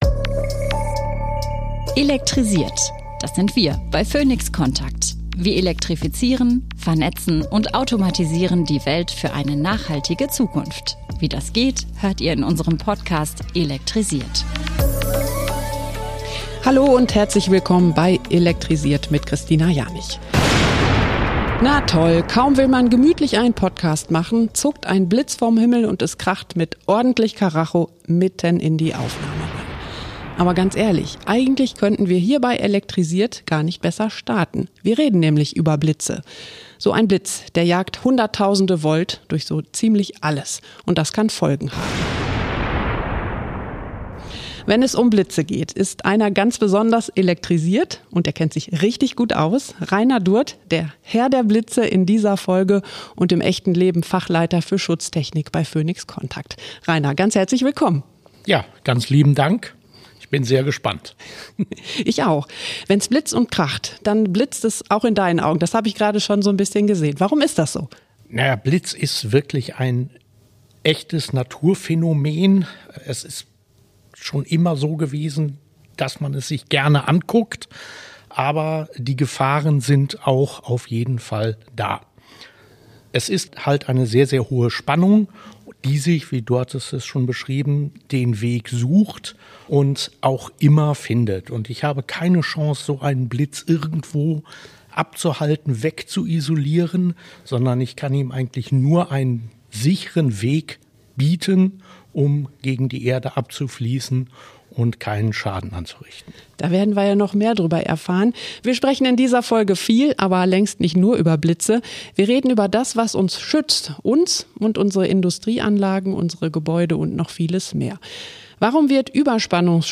Diese Folge beginnt mit einem ohrenbetäubenden Knall: Wenn ein Blitz einschlägt, entladen sich hunderttausende Volt – gigantische Spannungen, die sich ihren Weg suchen und vor nichts Halt machen. In der elektrifizierten Welt wird es jedoch immer wichtiger, Anlagen und Geräte elektrisch zu schützen....